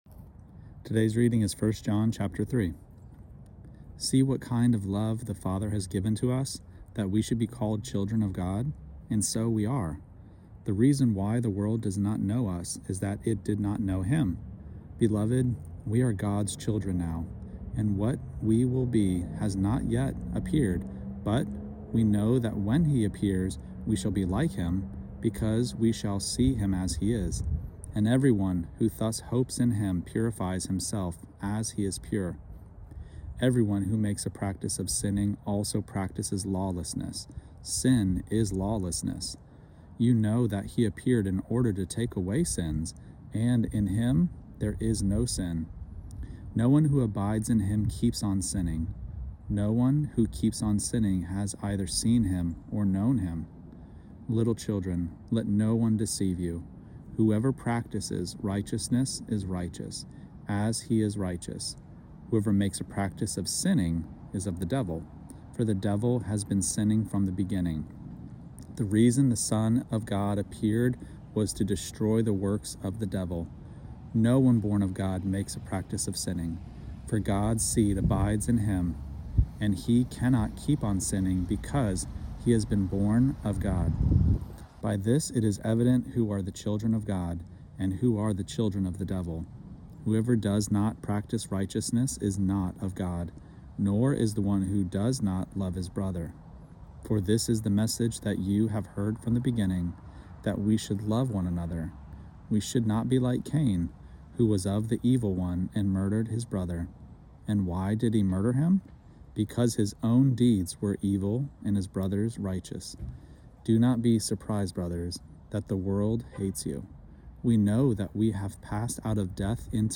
Daily Bible Reading (ESV) December 4: 1 John 3 Play Episode Pause Episode Mute/Unmute Episode Rewind 10 Seconds 1x Fast Forward 30 seconds 00:00 / 3:24 Subscribe Share Apple Podcasts Spotify RSS Feed Share Link Embed